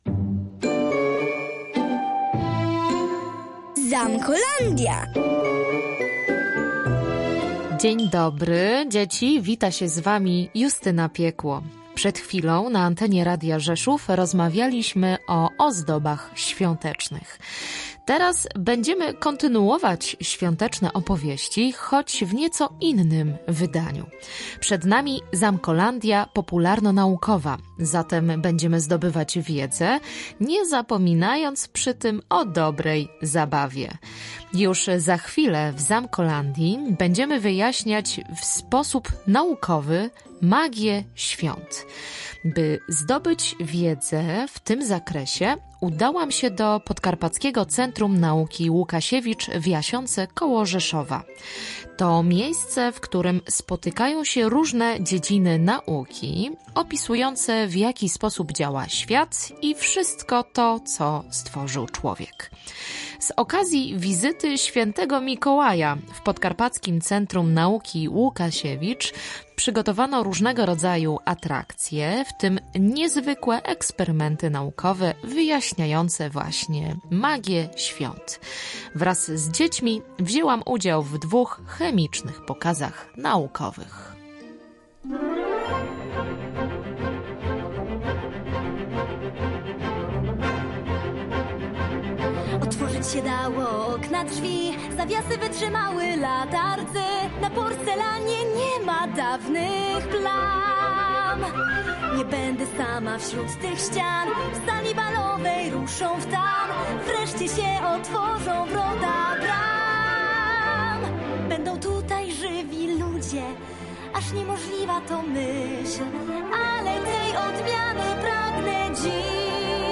Można i udowodniliśmy to w Zamkolandii. Z okazji wizyty św. Mikołaja w Podkarpackim Centrum Nauki Łukasiewicz przygotowano różnego rodzaju atrakcje, w tym niezwykłe eksperymenty naukowe. Była chemiczna choinka i znikający atrament z listu do św. Mikołaja.